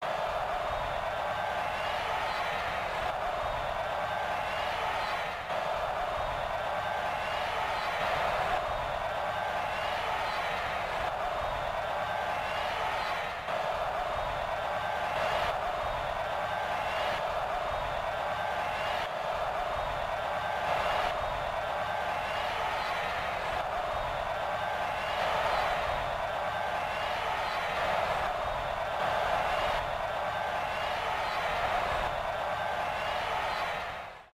CrowdNormal_1_V1%20(1)-y4snm3c8kj.mp3